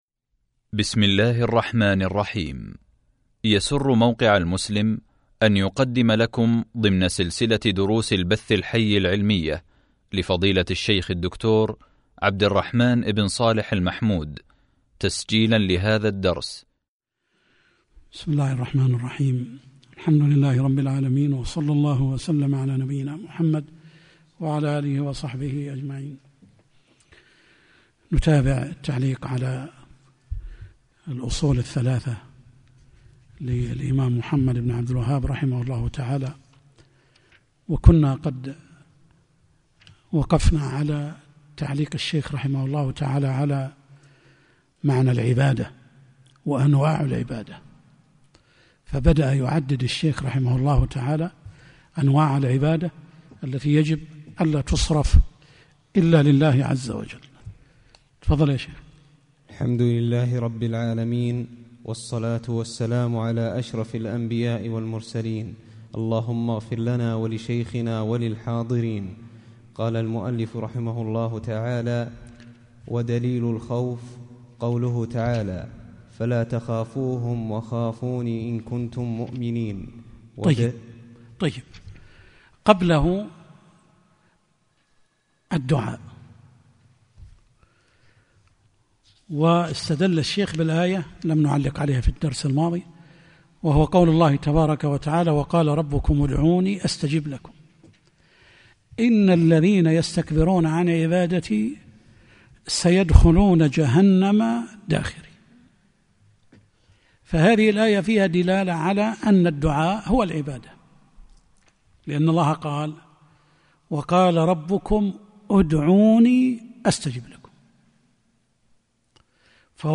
شرح الأصول الثلاثة | الدرس 10 | موقع المسلم